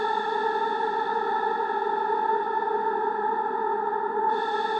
Index of /musicradar/sparse-soundscape-samples/Creep Vox Loops
SS_CreepVoxLoopB-06.wav